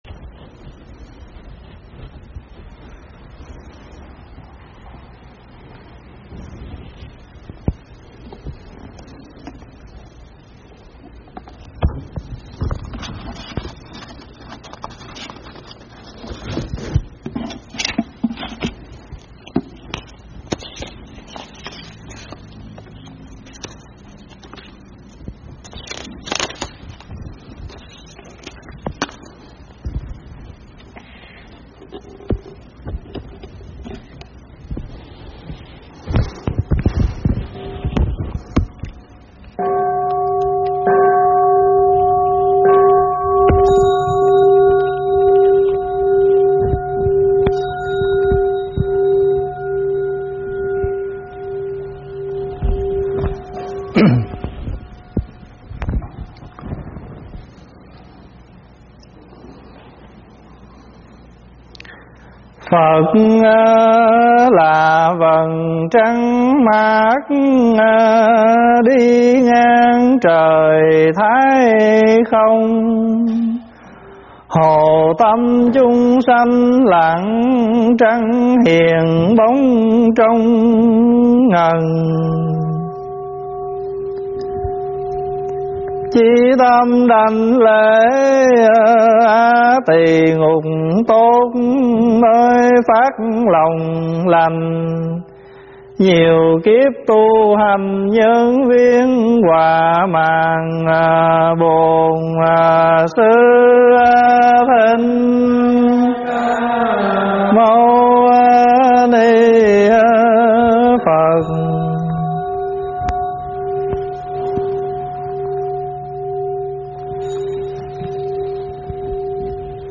Kinh tụng